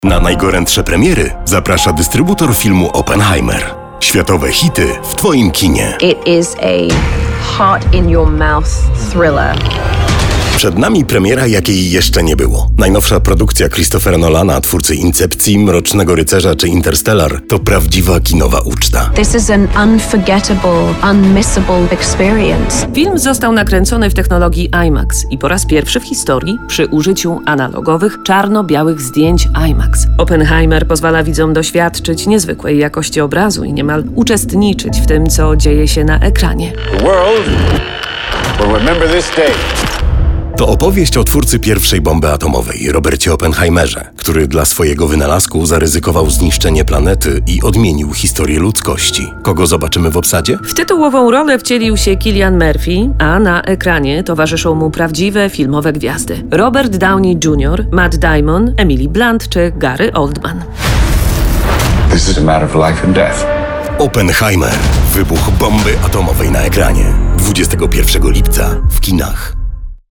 Female
Adult (30-50)
Deep, Jazzy, warm, rich alt
Radio Commercials